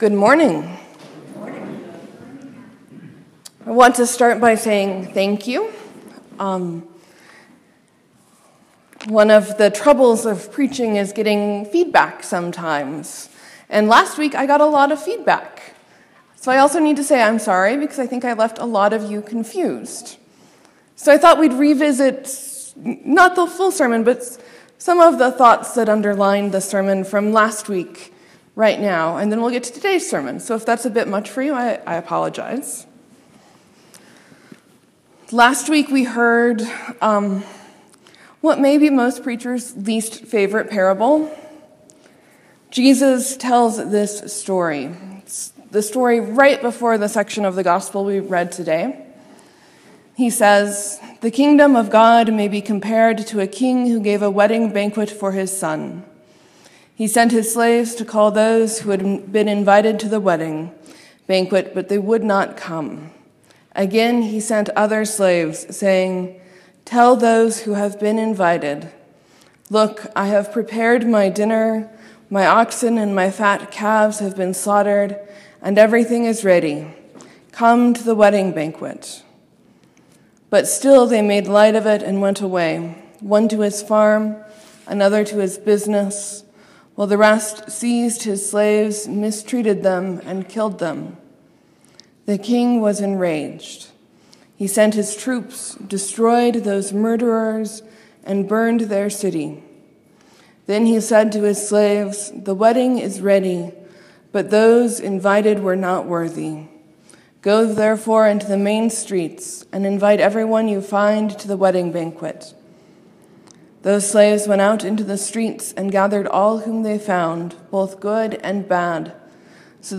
Sermon: “Should we pay taxes?” is an age-old question. When did it start and how does it intersect our lives today?